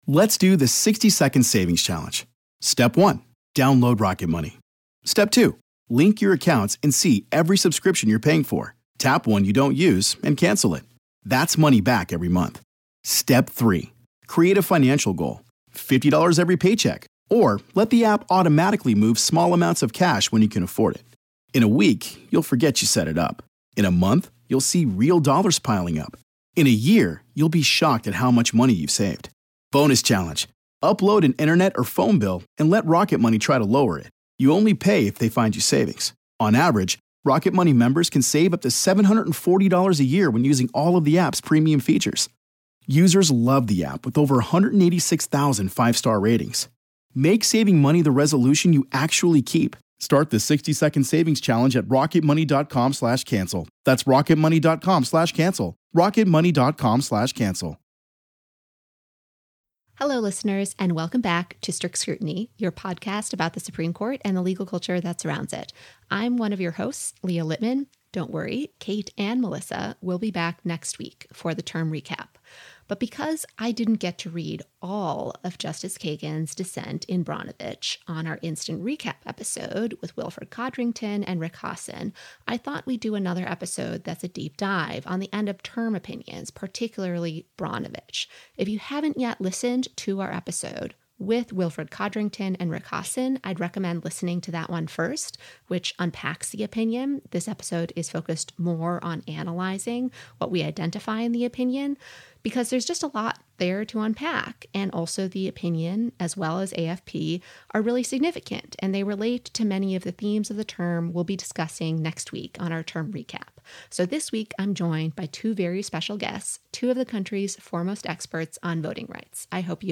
two voting rights experts